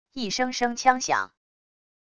一声声枪响wav音频